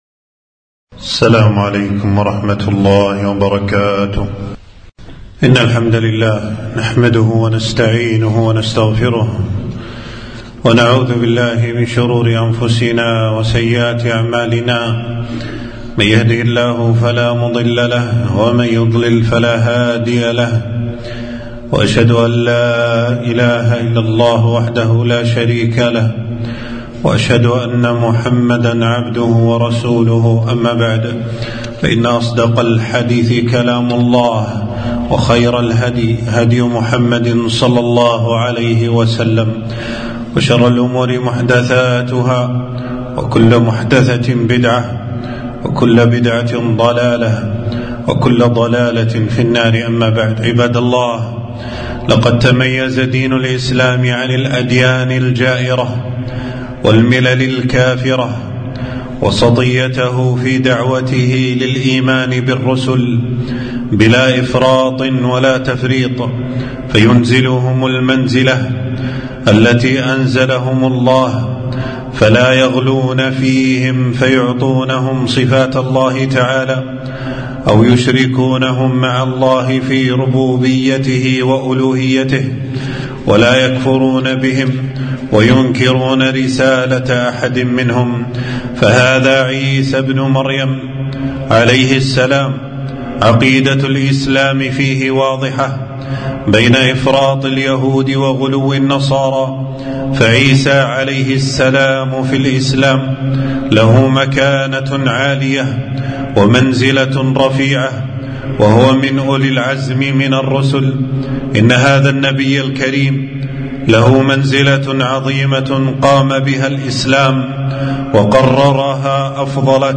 خطبة - دعوة نبي الله عيسى عليه السلام إلى التوحيد